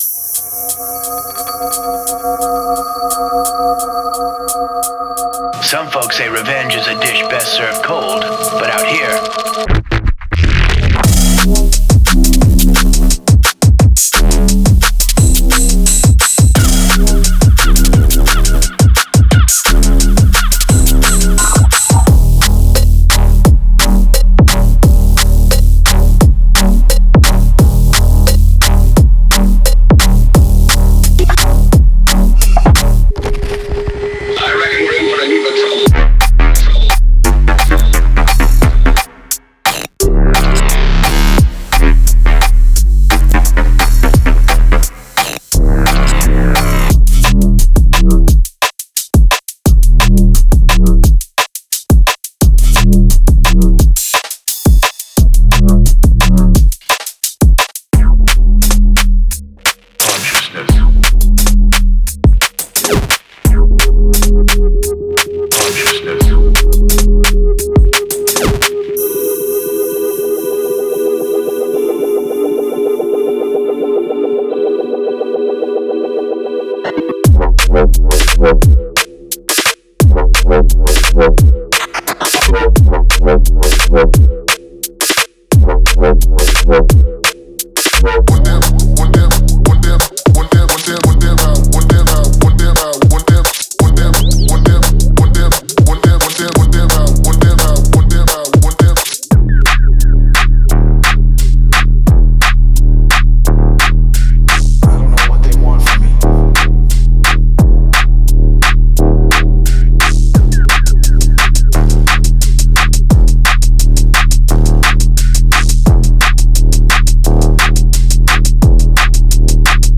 金属的で響きのあるリムショットがミックスを貫く
重量感と動きを兼ね備えた徹底加工されたベースライン
荒々しい質感を纏ったドラムやノイズレイヤーが即効性のあるパンチを与える
歪んだボーカル、進化するアトモスフィア、ひねりの効いたFXが奥行きと緊張感をもたらす
デモサウンドはコチラ↓
Genre:Drum and Bass
24Bit 44.1KHZ